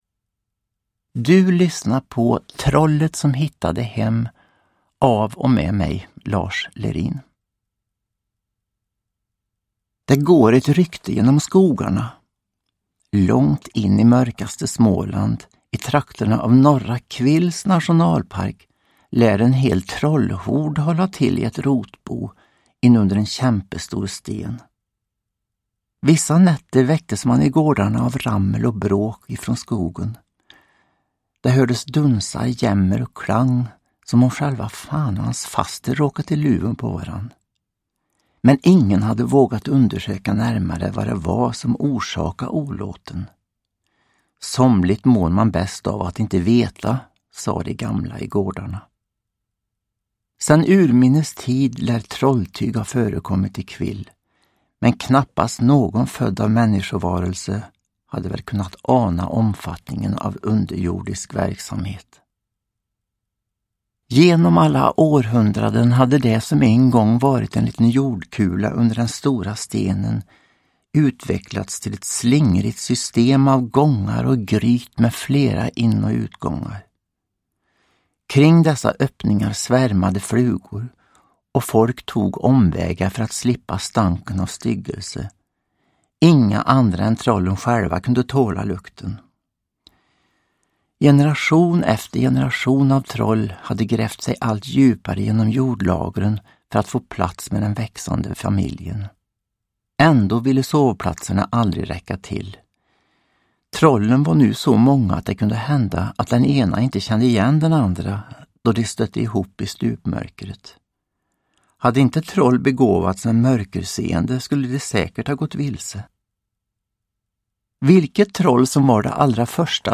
Uppläsare: Lars Lerin
Ljudbok